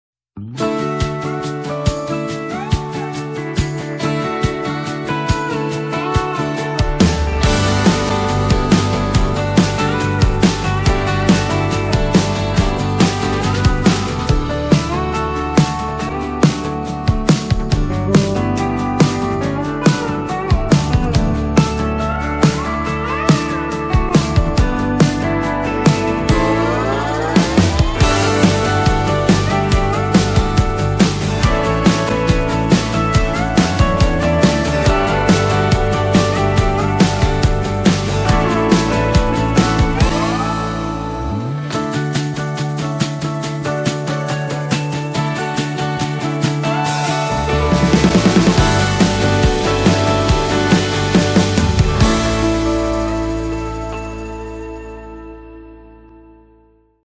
virtual Instrument